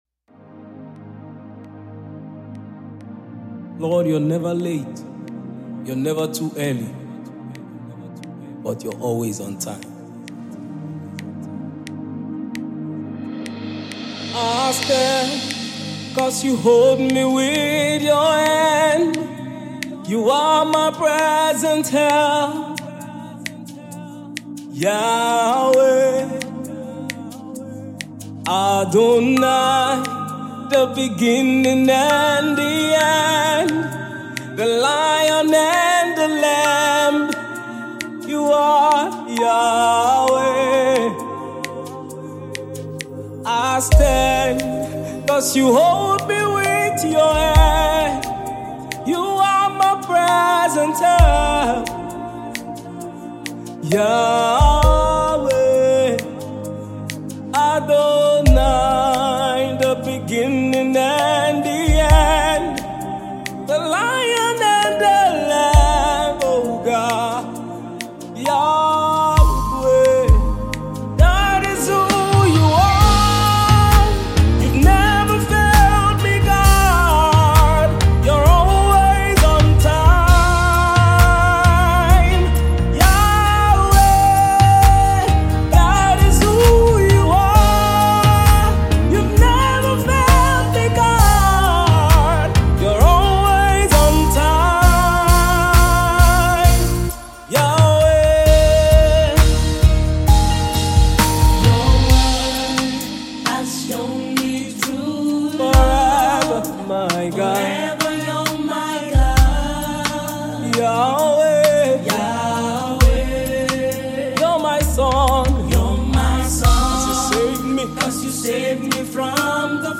powerful packaged worship
contemporary Gospel song